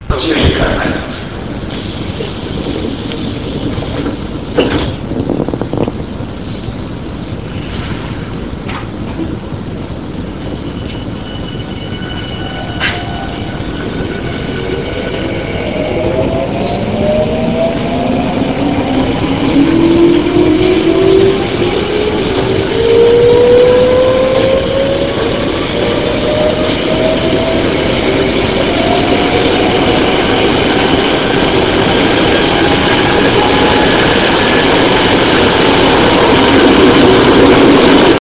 ０５系ワイドドア車減速音 東海神→飯山満 51.4Kb RealAudio形式
０５系第１４編成のみで聞こえるのがこの音です。